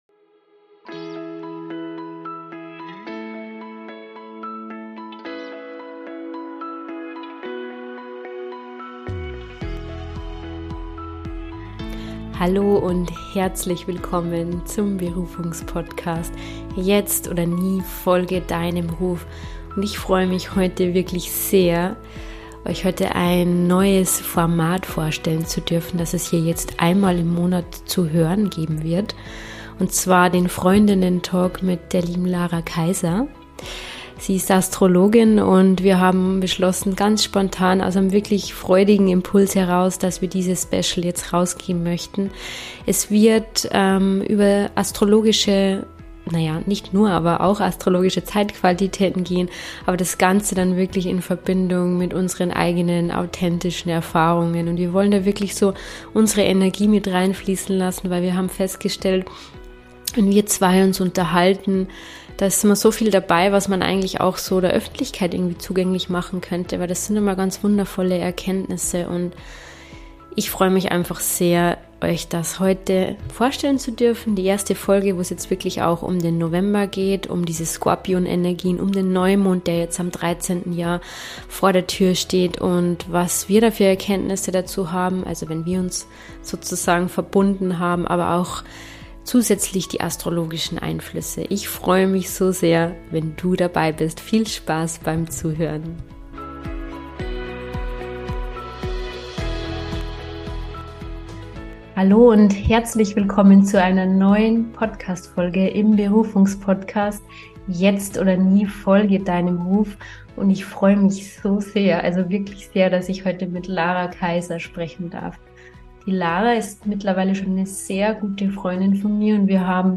Beschreibung vor 2 Jahren Wir freuen uns sehr, dir heute unsere erste Folge über aktuelle Zeitqualitäten im Freundinnen-Talk vorstellen zu dürfen.